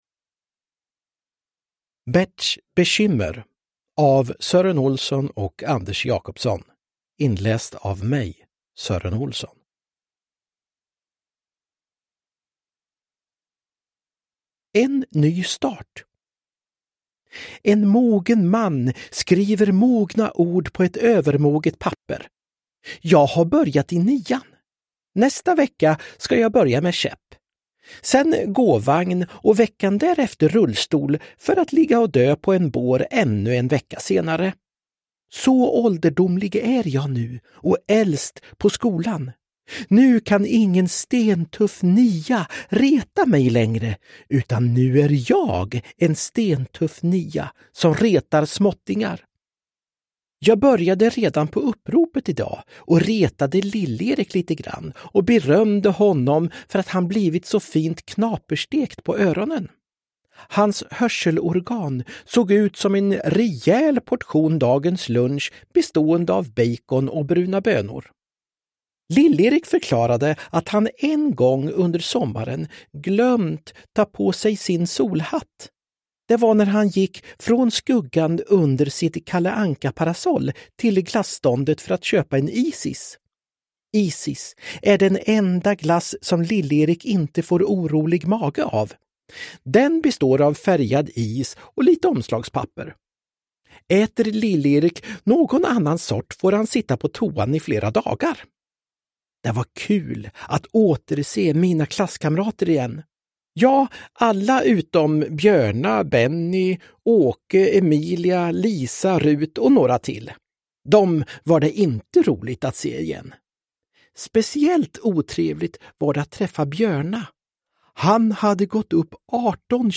Berts bekymmer – Ljudbok – Laddas ner
Uppläsare: Sören Olsson